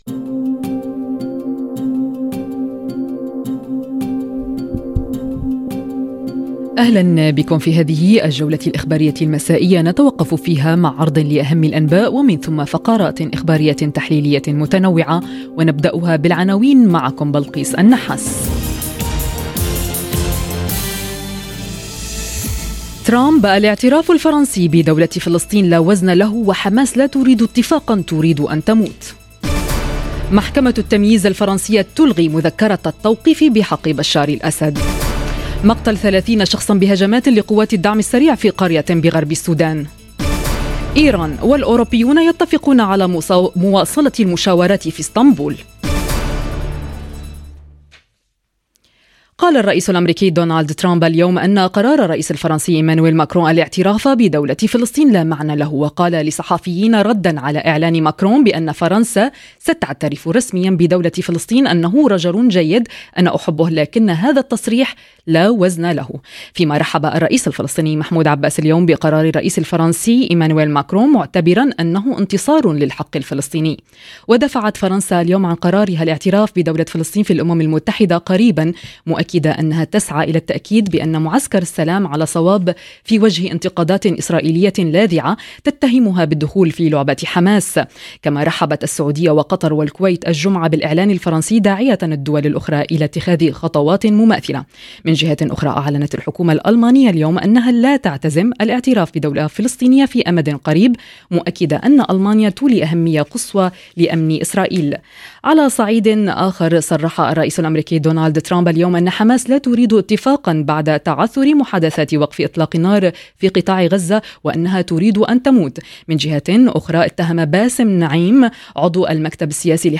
نشرة أخبار المساء: رد فعل ترامب على الاعتراف الفرنسي بدولة فلسطين، ومحكمة التمييز الفرنسية تلغي مذكرة التوقيف بحق بشار الأسد - Radio ORIENT، إذاعة الشرق من باريس